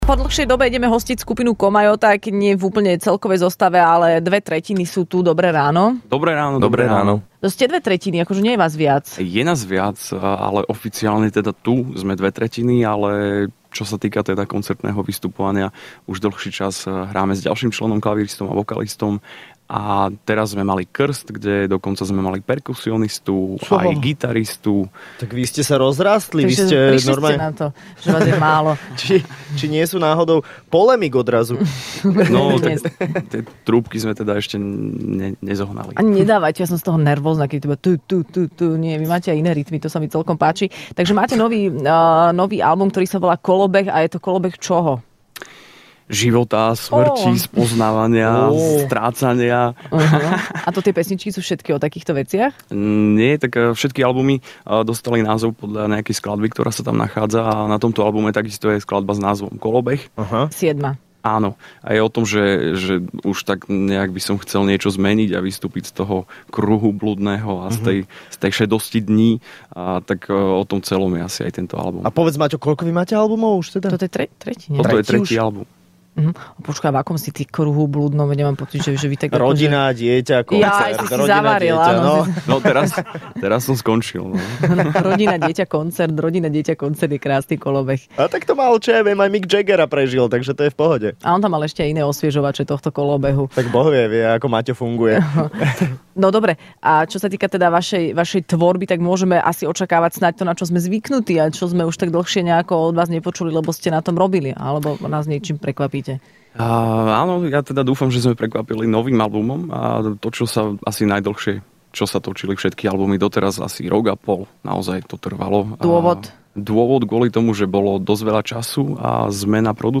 Hosťom v Rannej šou bola skupina Komajota, ktorá prišla predstaviť svoj nový album a v exkluzívnej premiére zahrali aj svoju novú pieseň...